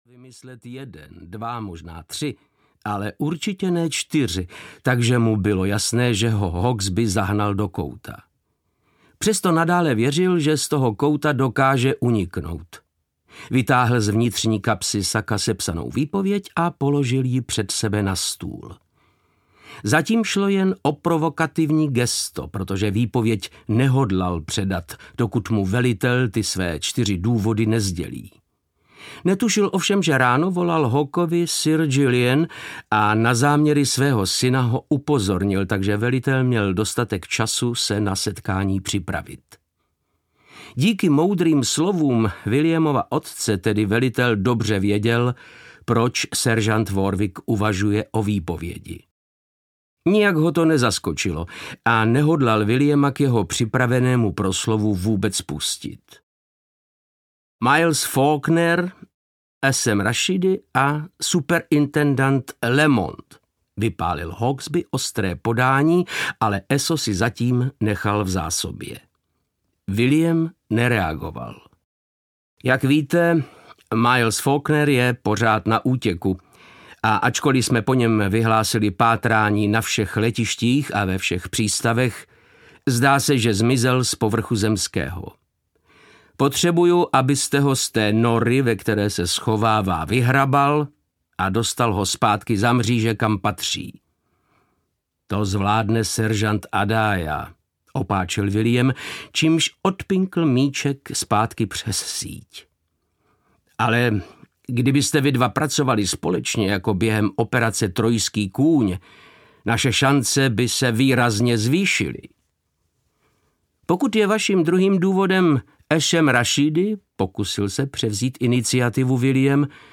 Přimhouřit oko audiokniha
Ukázka z knihy
• InterpretVáclav Knop